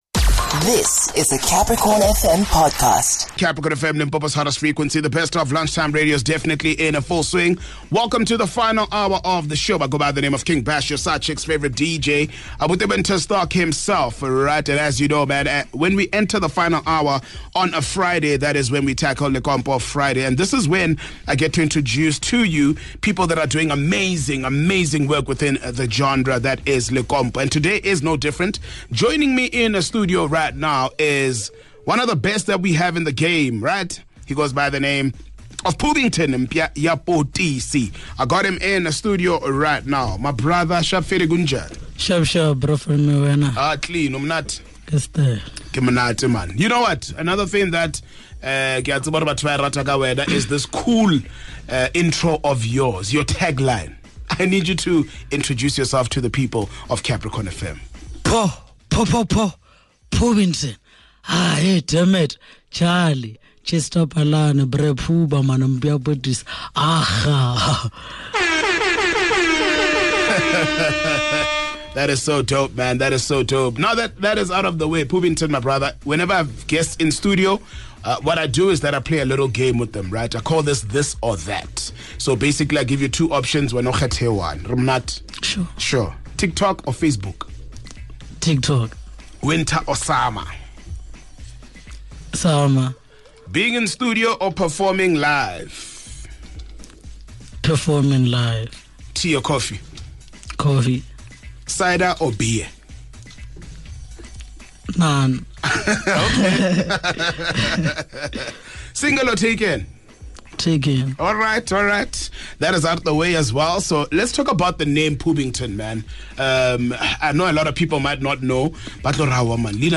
Reflecting on his challenges the listeners of the Capricorn Adventure come on to advise him to keep going as he is really talented.